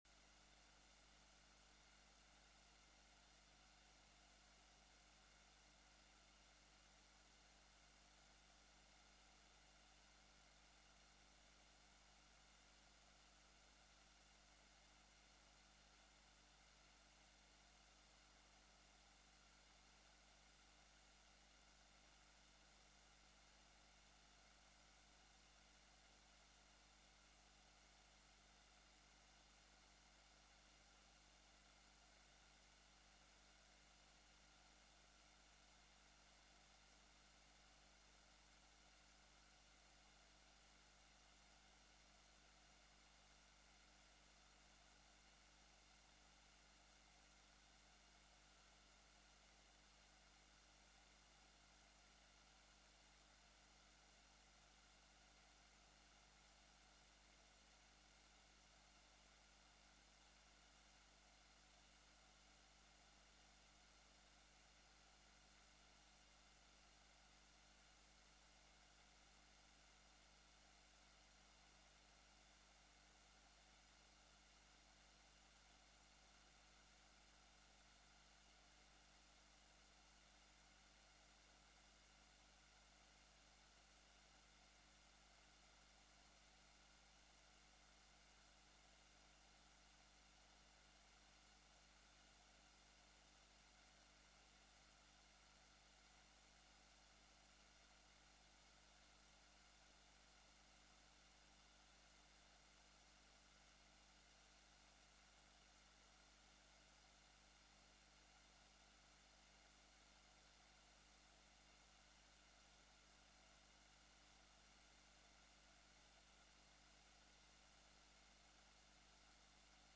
説 教 「天に上げられ、神の右に座した」